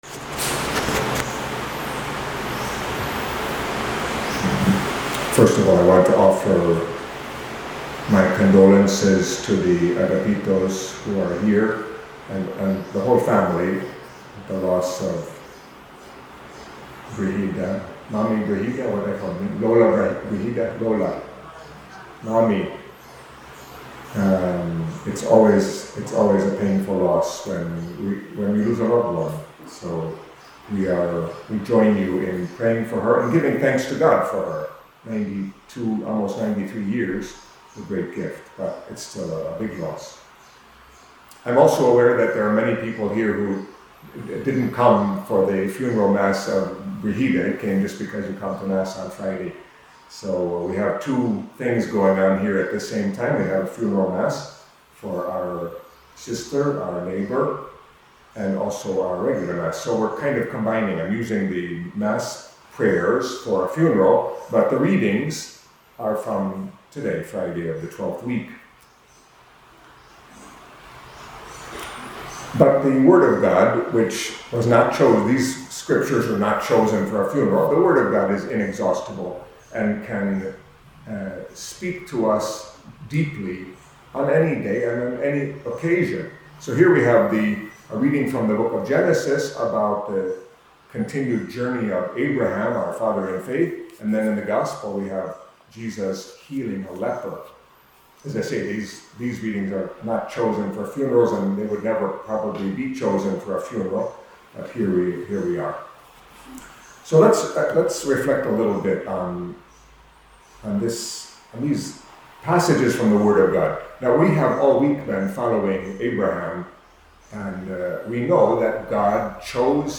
Catholic Mass homily for Friday of the 12th Week in Ordinary Time